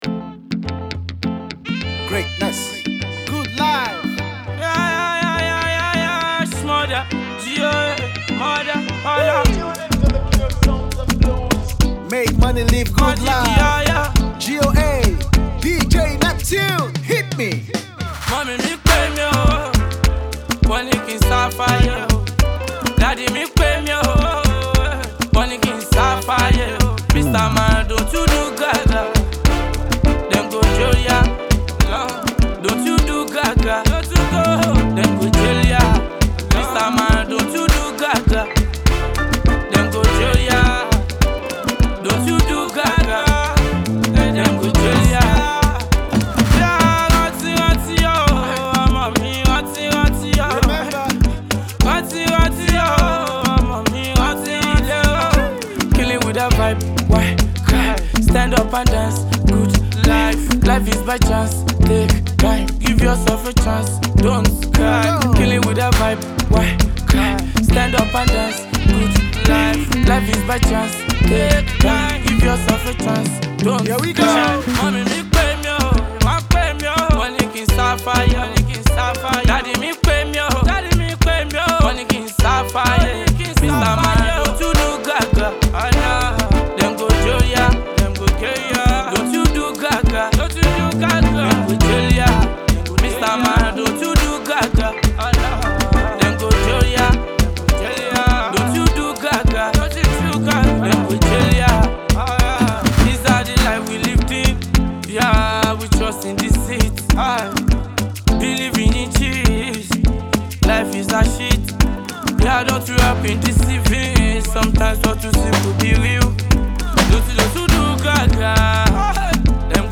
Nigeria Music
massive afro beats tune